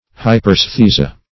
Search Result for " hyperaesthesia" : The Collaborative International Dictionary of English v.0.48: Hyperaesthesia \Hy`per*[ae]s*the"si*a\, n. [NL., fr. Gr.